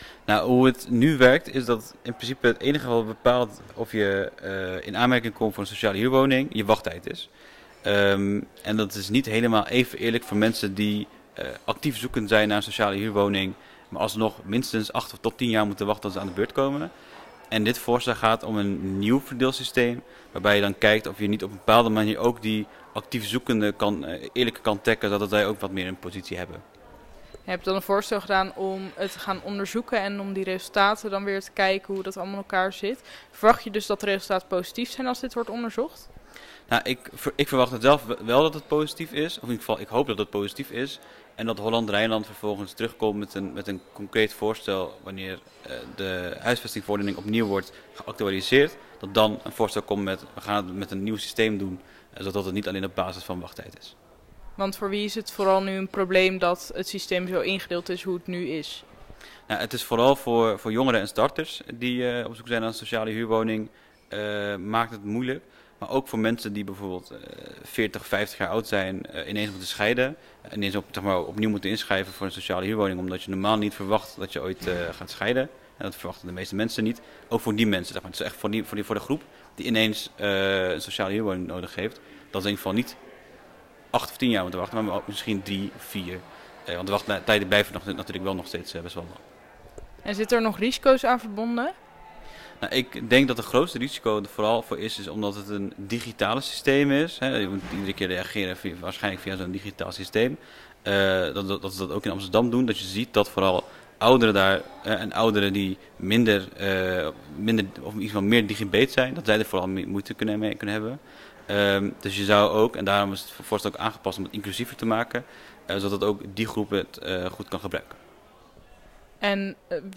gaat in gesprek met raadslid van GroenLinks, Ahmet Kargin, over het amendement voor een eerlijk woonruimteverdeelsysteem